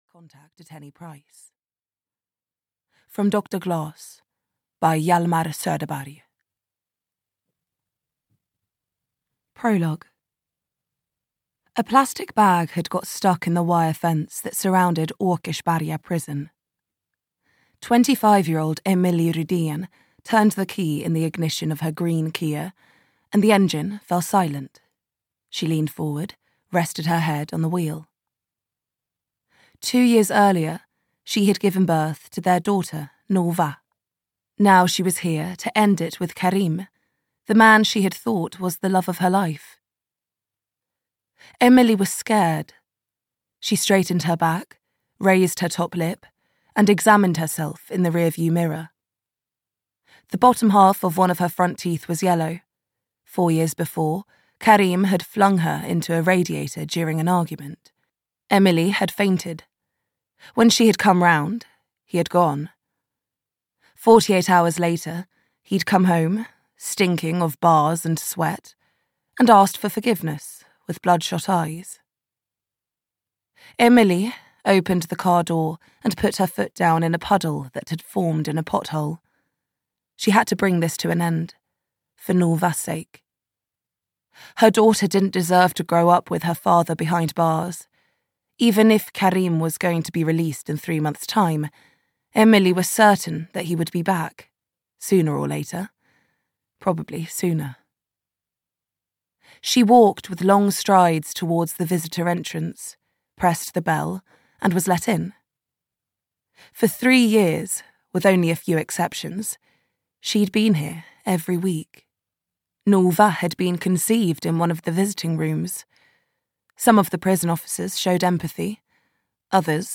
Femicide (EN) audiokniha
Ukázka z knihy